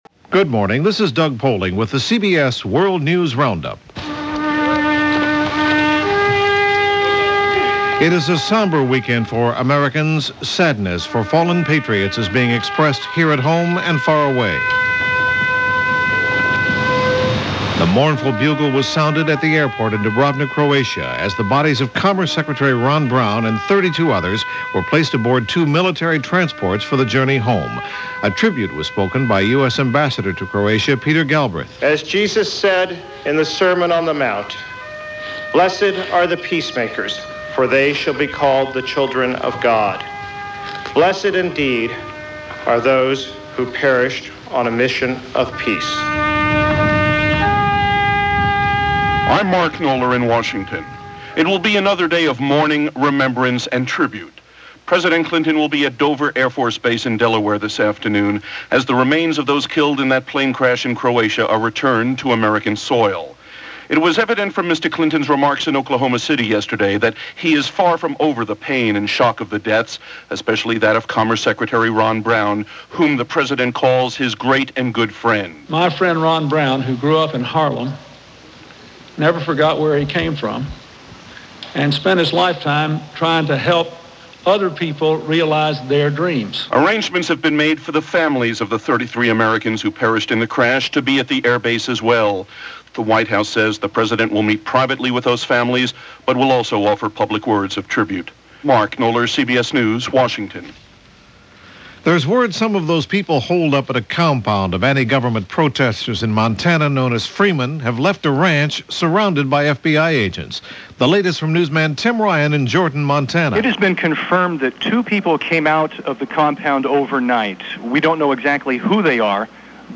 All this, and a whole pile more mayhem and grief, this April 6th in 1996, as reported by The CBS World News Roundup.